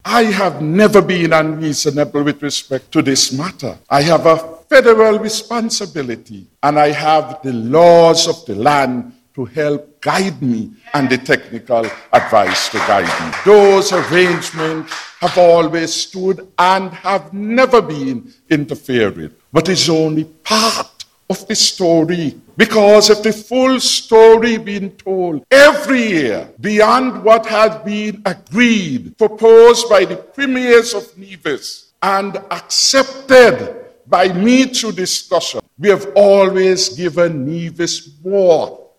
During that forum, Prime Minister Harris addressed the nation about Nevis’ Fair Share.